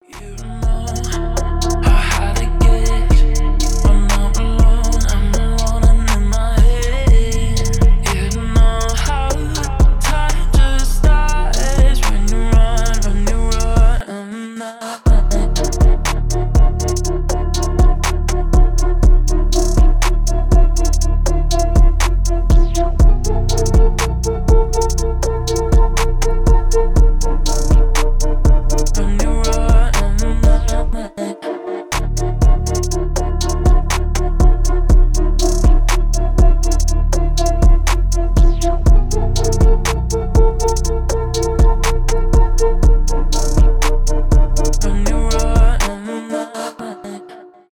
chill trap , медленные
electronic , чувственные